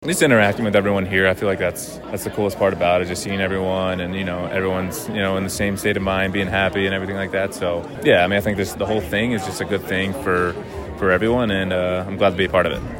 myFM had the chance to speak with some of the head table  guests ahead of the sit-down portion of the evening, who shared why they wanted to get involved in such a special event.